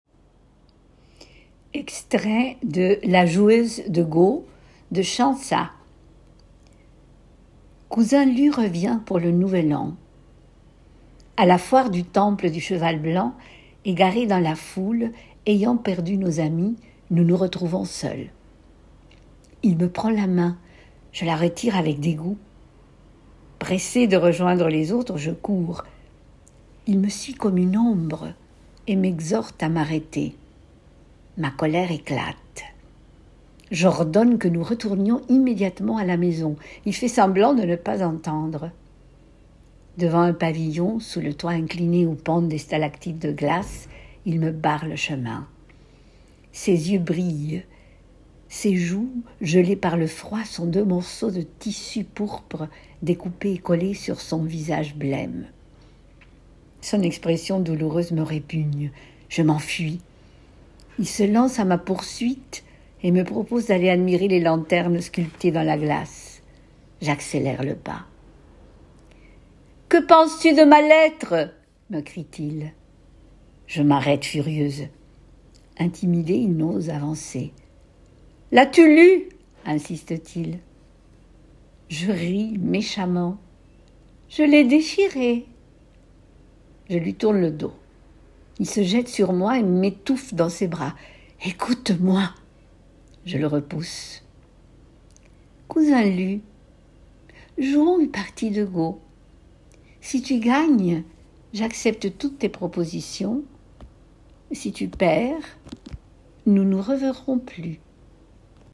Extrait VOIX 3 pour livre audio